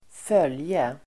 Uttal: [²f'öl:je]